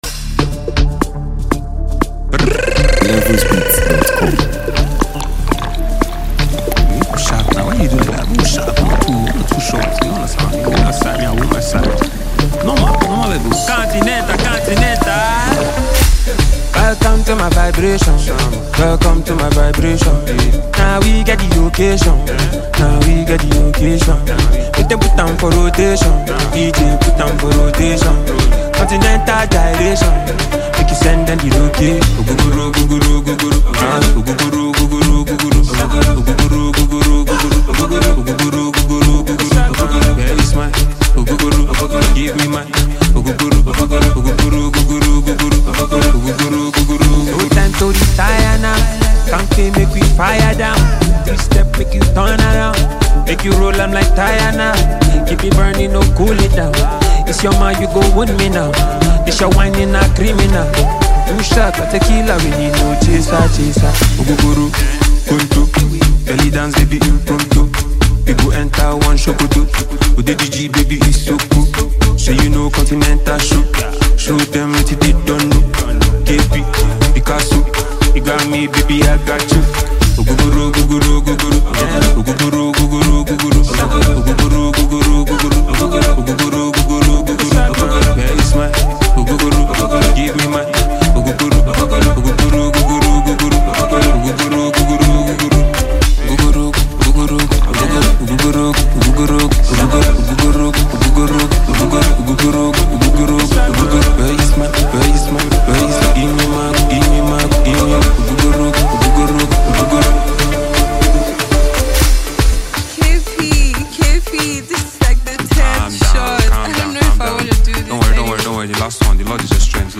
Nigeria Music 2025 2:32